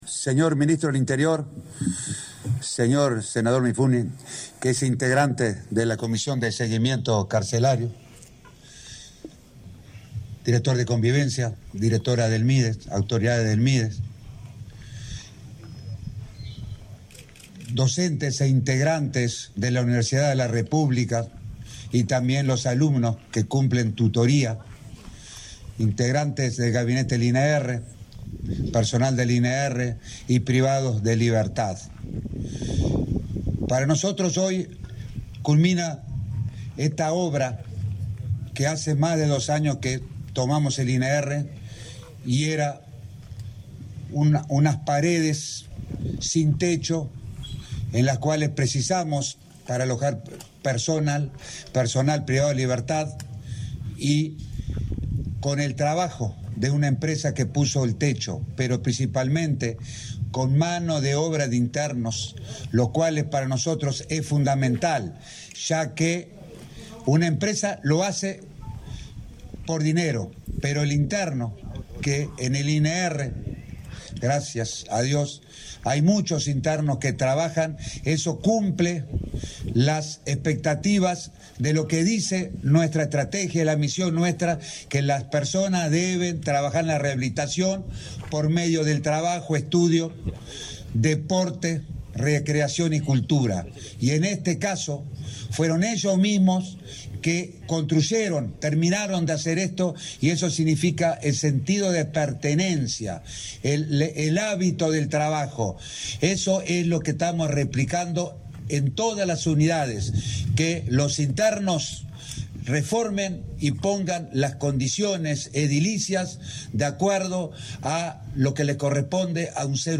Conferencia de prensa por la inauguración de pabellones para universitarios en la cárcel de Punta de Rieles
Conferencia de prensa por la inauguración de pabellones para universitarios en la cárcel de Punta de Rieles 09/06/2022 Compartir Facebook X Copiar enlace WhatsApp LinkedIn La cárcel de Punta de Rieles dispone de un nuevo pabellón, con capacidad para 60 personas que cursan diferentes carreras terciarias. El ministro de Interior, Luis Alberto Heber, y el director del Instituto Nacional de Rehabilitación (INR), Luis Mendoza, participaron en la inauguración, realizada este 9 de junio.